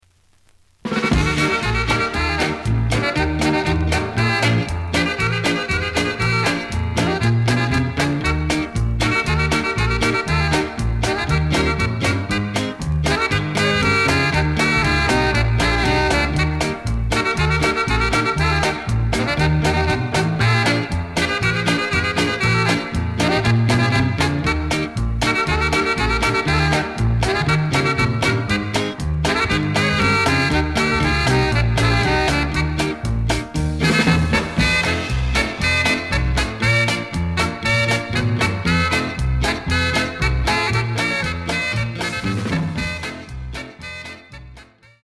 The audio sample is recorded from the actual item.
●Format: 7 inch
●Genre: Latin